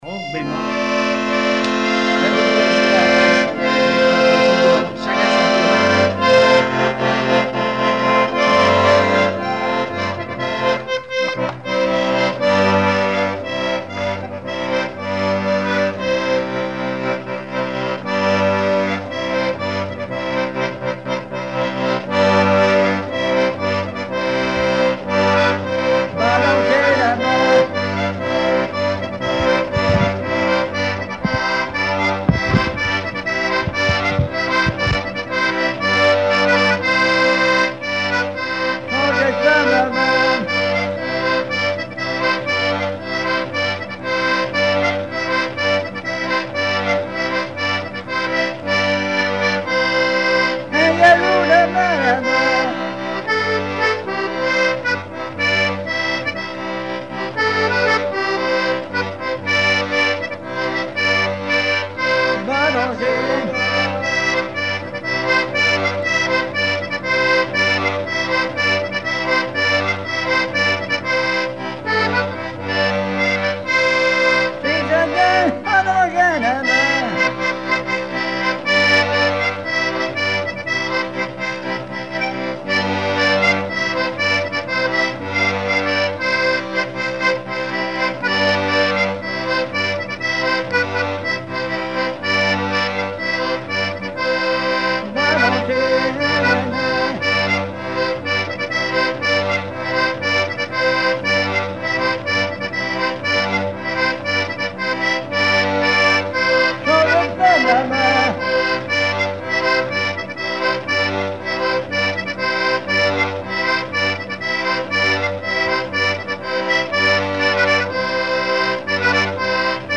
danse : quadrille
chansons populaires et instrumentaux
Pièce musicale inédite